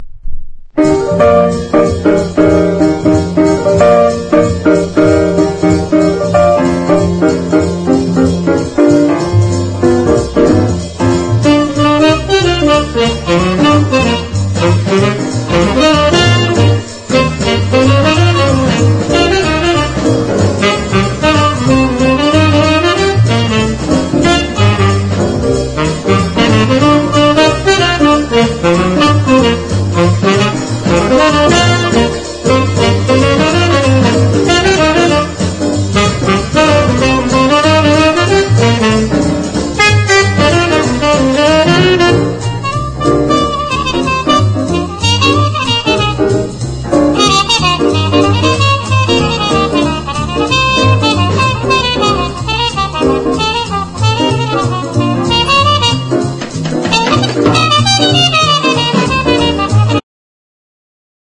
ポピュラリティ溢れるアーリー・ソウル/R&Bナンバーを収録しています！